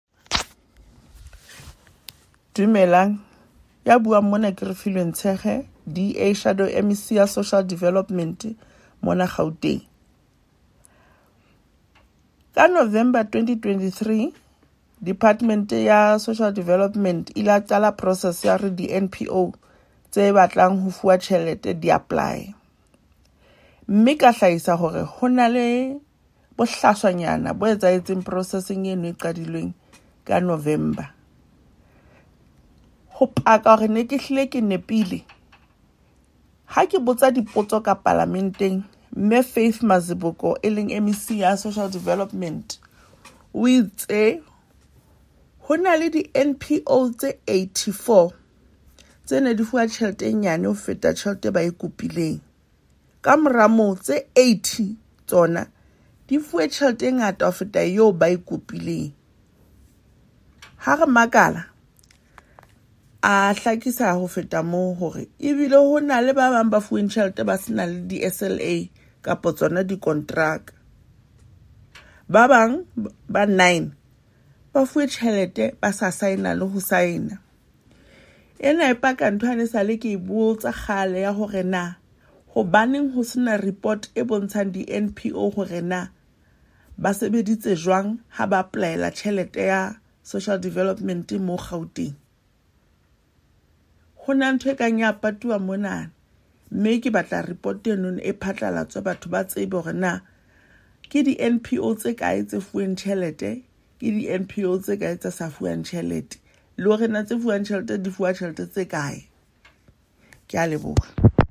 here from DA MPL, Refiloe Nt’sekhe.